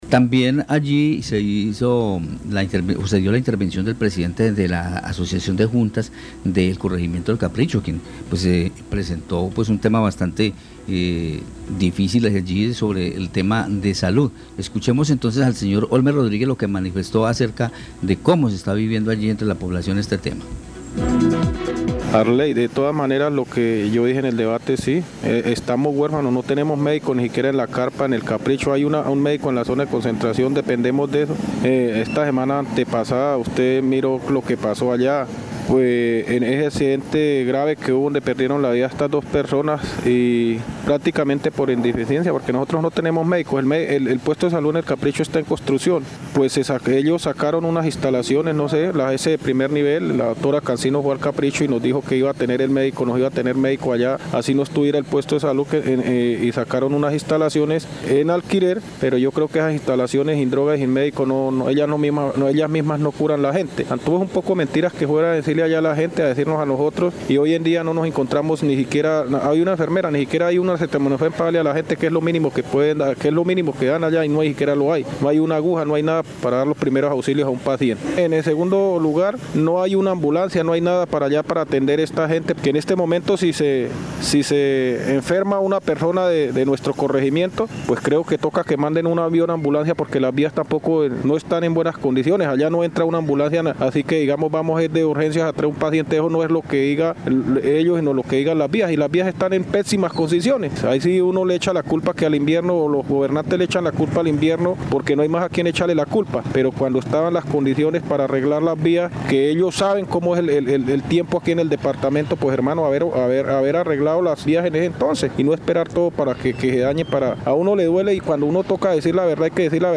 usuaria de la salud.